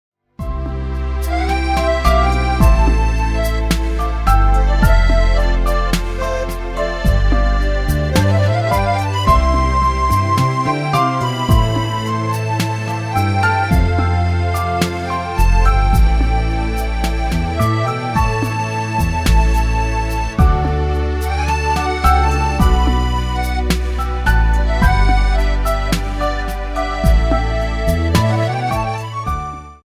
Musical - CD mit Download-Code